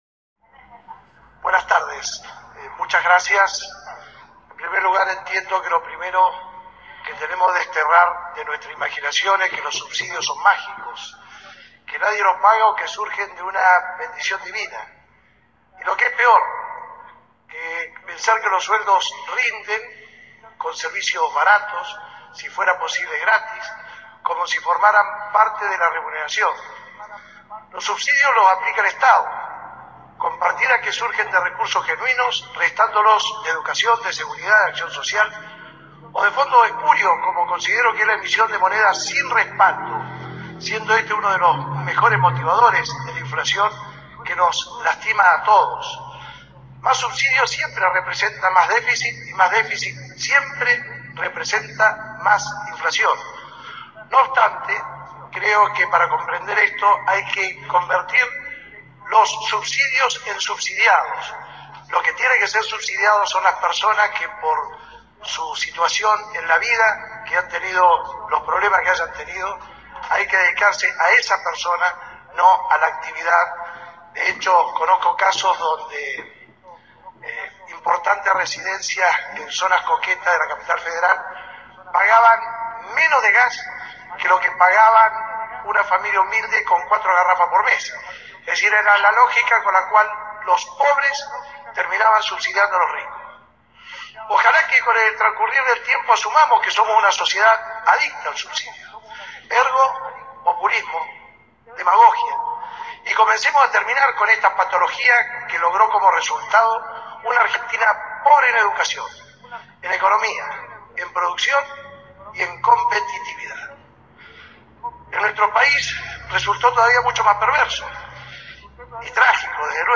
Hoy se reanudó la Audiencia Pública de Tarifas de Gas luego de haber quedado suspendida por los actos de violencia suscitados por miembros del gremio ATE en el mediodía de ayer al ingreso al Concejo Deliberante de la ciudad. Pasadas las 14 horas fue el turno de disertar del intendente de Neuquén Horacio "Pechi" Quiroga.